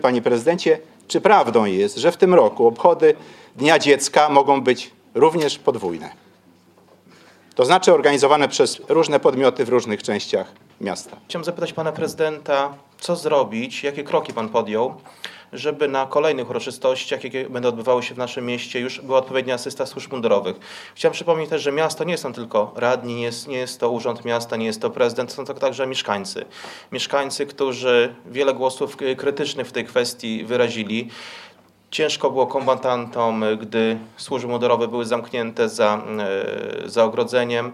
O sprawę pytał w środę (31.05) na sesji Rady Miejskiej Zdzisław Przełomiec z Klubu „Łączą nas Suwałki”.